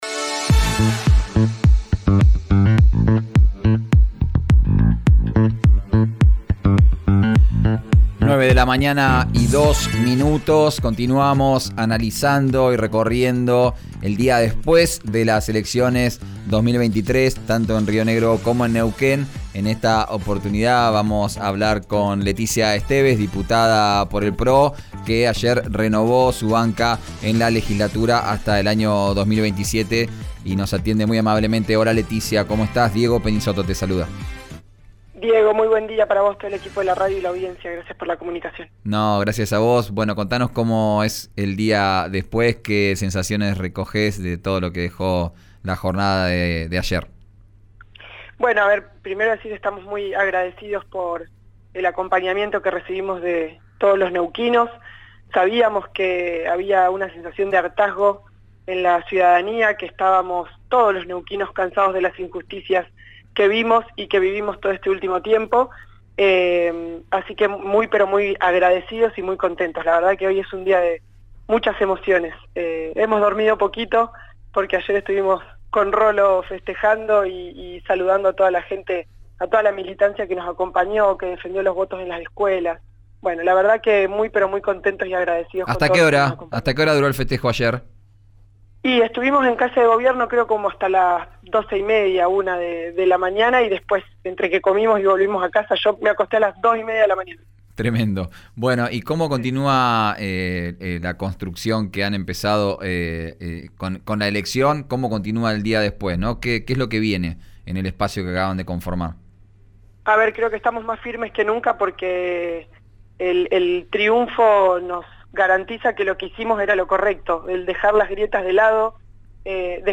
Escuchá a la diputada provincial Leticia Esteves en RÍO NEGRO RADIO.